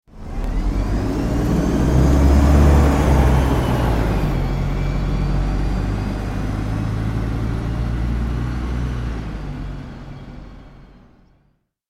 دانلود آهنگ اتوبوس 3 از افکت صوتی حمل و نقل
جلوه های صوتی
دانلود صدای اتوبوس 3 از ساعد نیوز با لینک مستقیم و کیفیت بالا